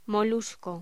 Locución: Molusco bivalvo